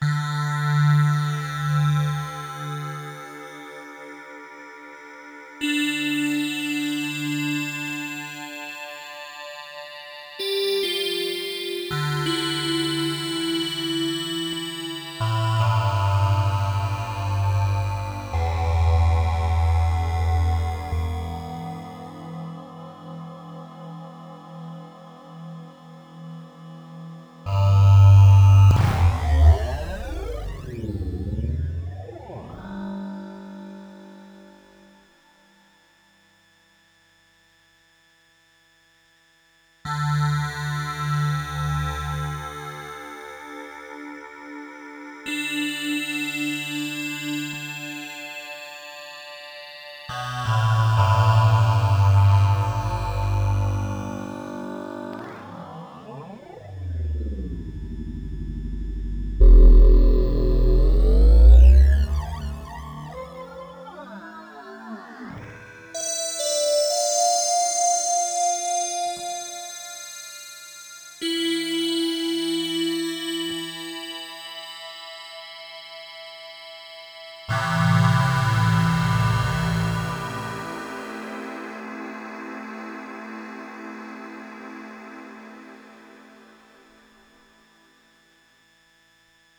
Syncro sur chaque note jouée, ya donc un "fondu" entre l'OSC 1>2 . Pour qu'il paraisse "Morph" un dégradé des Shapes est programmé (leurs index se déplacent) lors du passage du 1 au 2. L'Env3 permets un retard sur l'OSC 2 , pour qu"il vienne après et également assigné sur la Balance (ainsi, commence au début l'OSC 1, puis, en suivant, le 2).
MORPHING-B (DEMO Audio)